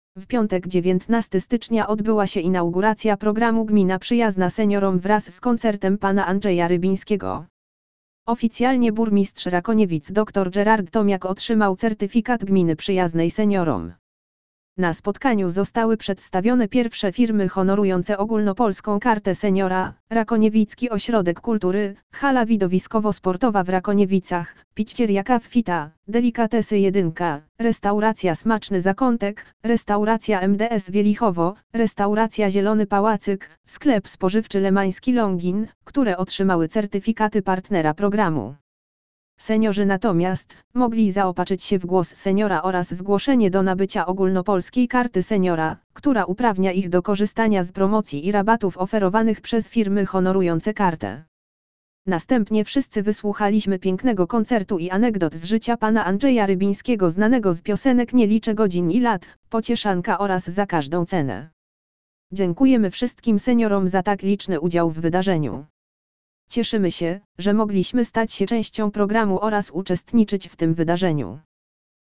W piątek 19 stycznia odbyła się Inauguracja Programu Gmina Przyjazna Seniorom wraz z Koncertem Pana Andrzeja Rybińskiego. Oficjalnie burmistrz Rakoniewic dr Gerard Tomiak otrzymał certyfikat Gminy Przyjaznej...